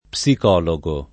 psicologo [ p S ik 0 lo g o ] s. m.; pl. ‑gi